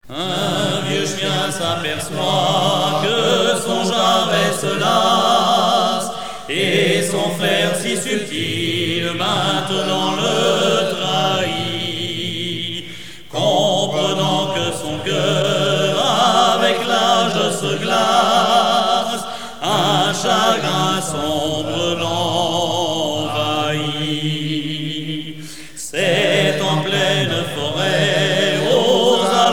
circonstance : rencontre de sonneurs de trompe
Pièce musicale éditée